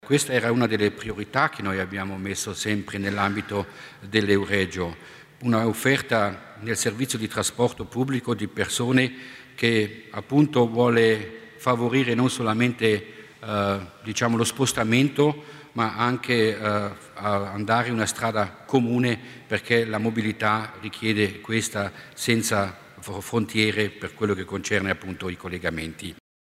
L'Assessore Mussner spiega le novità in tema di trasporto ferroviario